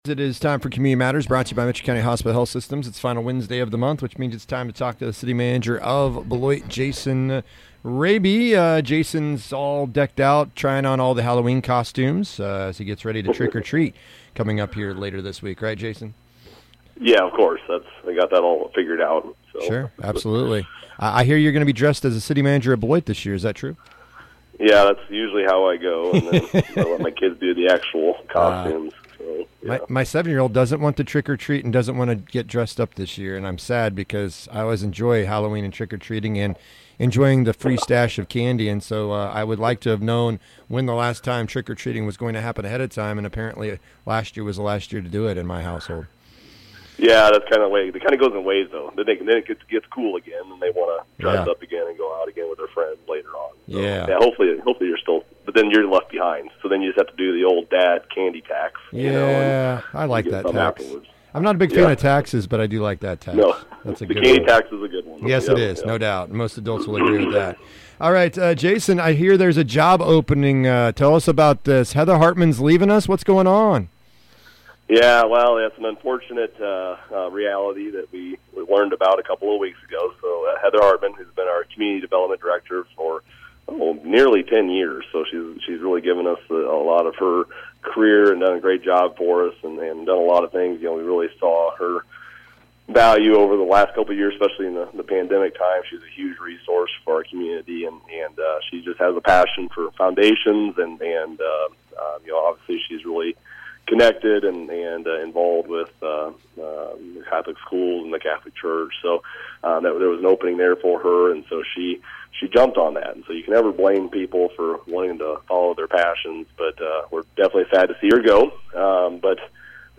Jason joins us to discuss the current projects and things citizens should know about in regards to the City of Beloit.